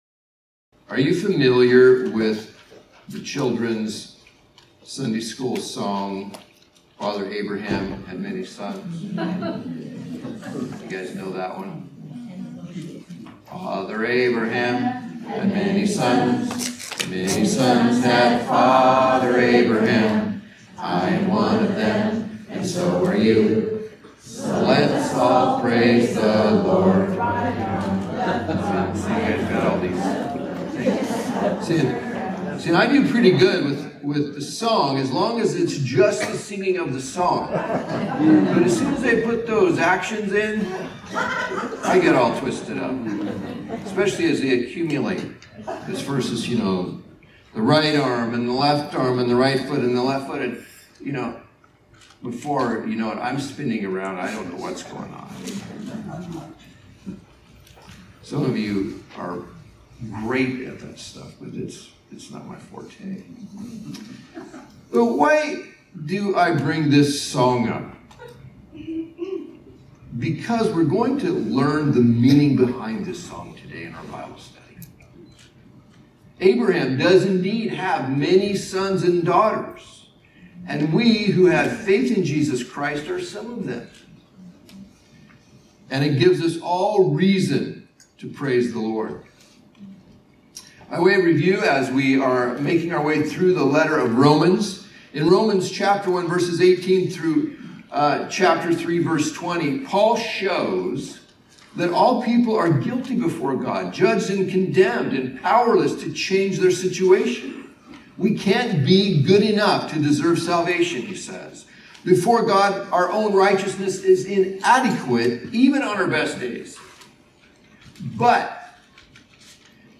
Recent Sundays